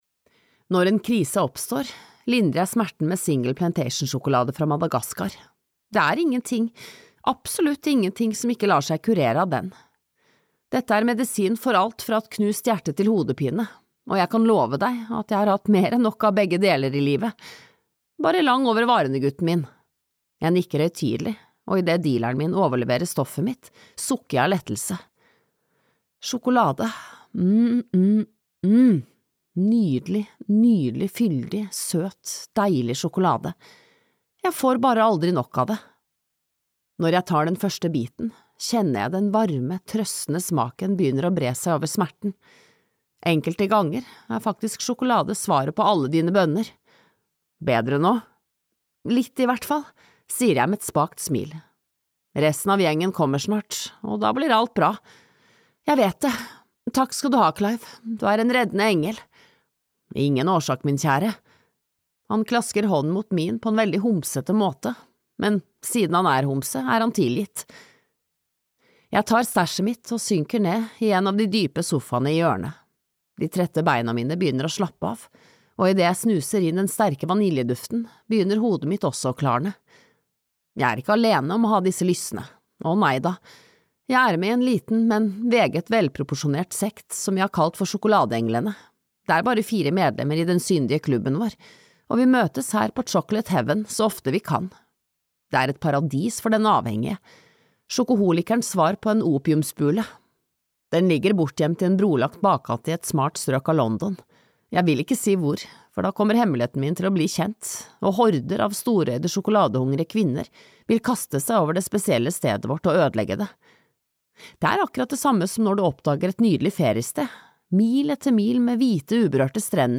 Vi møtes på Chocolate Heaven (lydbok) av Carole Matthews